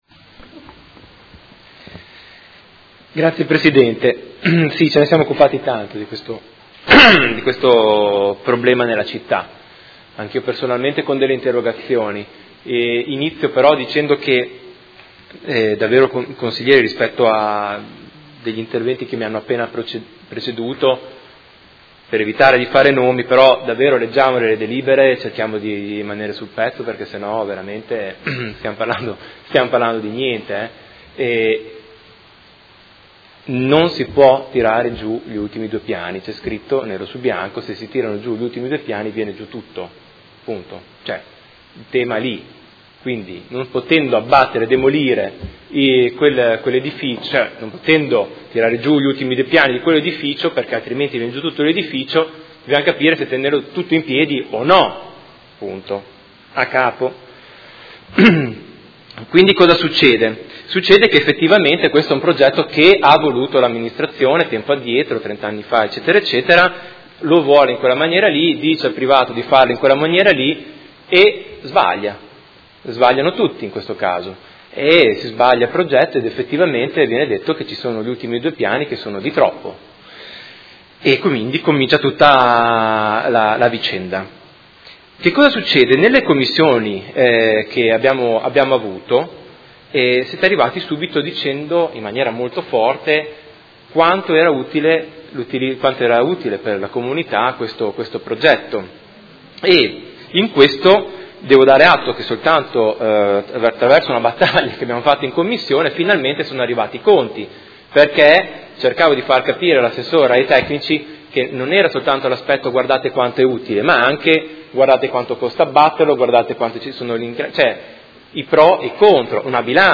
Seduta del 20/12/2018. Dibattito su proposta di deliberazione: Dichiarazione di prevalente interesse pubblico ai fini del mantenimento dell'immobile sito in Modena, viale Trento Trieste, catastalmente identificato presso il Catasto Urbano di Modena al foglio 144, mappale 141 subalterni 10,13,18, 19, 21, 23, 25, 36, 27, 28, 29 comprese aree di sedime e aree pertinenziali, ex Ditta B.A. Service S.r.l. ai sensi del comma 5 art. 13 Legge Regionale 23/2004, e mozione